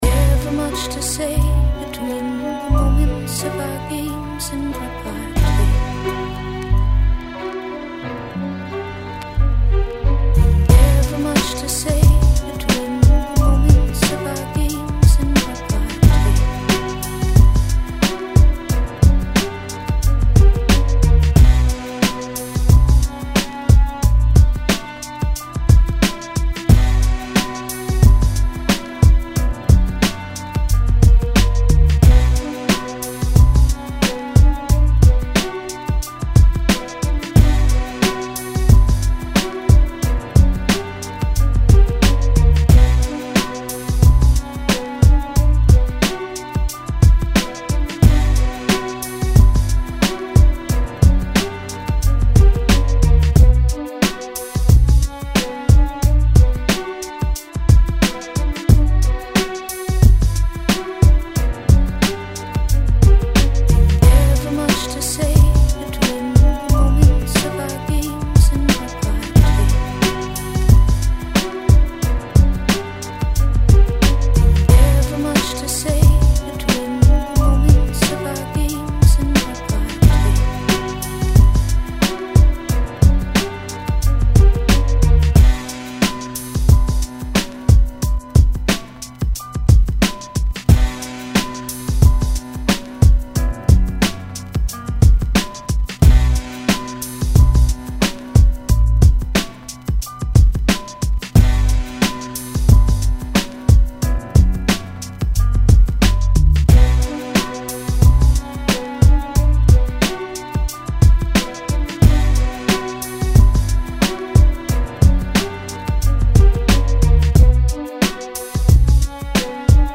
Sampled joint with a female singer on the hook.
90 BPM.